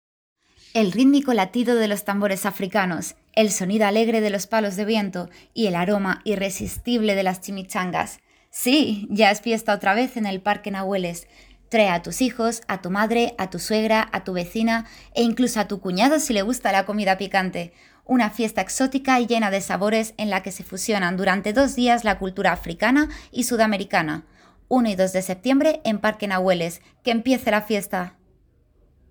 kastilisch
Sprechprobe: Werbung (Muttersprache):